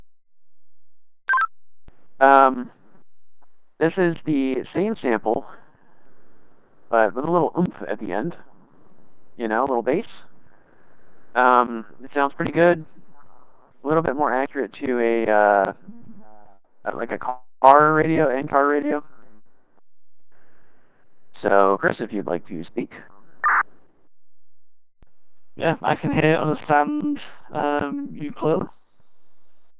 These voice effects combines the vocoder with pre and post-effect EQ customizations for the best possible clarity.
#2 - Vocoder with Additional Bass
vocoder_example_2.mp3